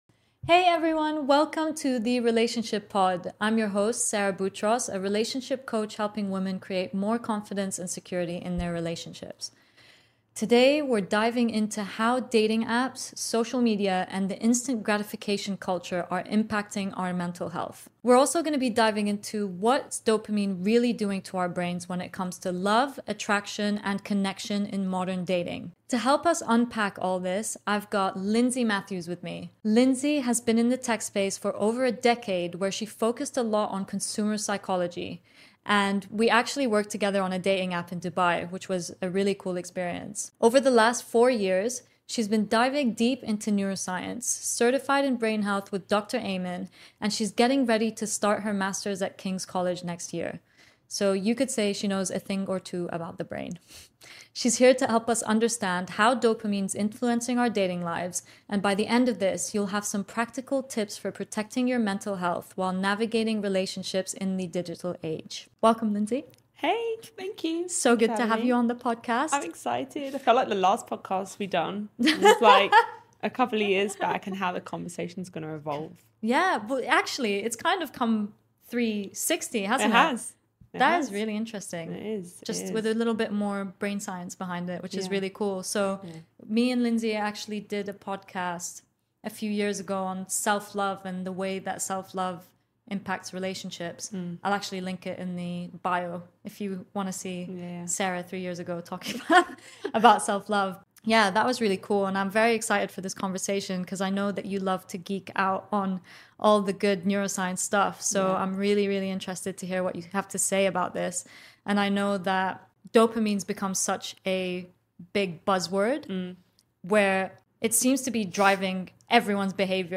Join us for a thoughtful conversation packed with psychological insights and practical tips to help you build meaningful connections in a digital world.